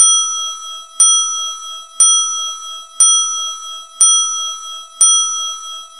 longbell Final.mp3